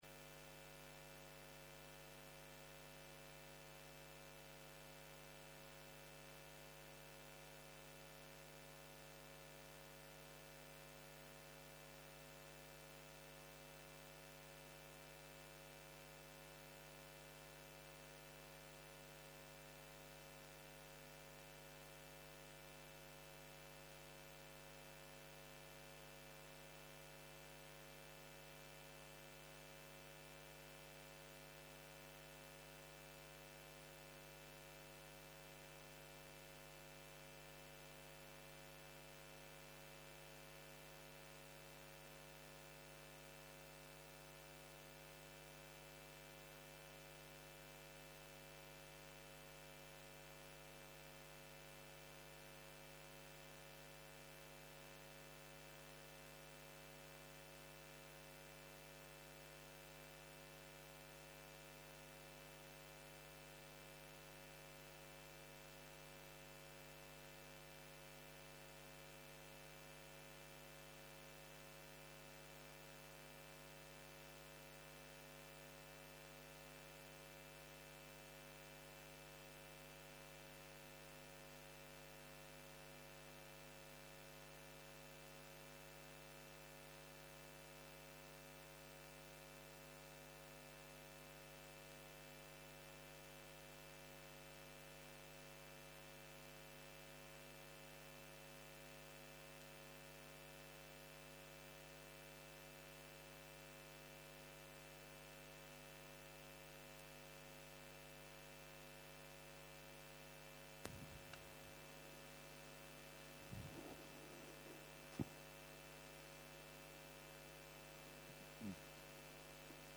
יום 3 - צהריים - מדיטציה מונחית - נעים, לא נעים, נייטרלי - הקלטה 6
יום 3 - צהריים - מדיטציה מונחית - נעים, לא נעים, נייטרלי - הקלטה 6 0:00 0:00 סוג ההקלטה: Dharma type: Guided meditation שפת ההקלטה: Dharma talk language: Hebrew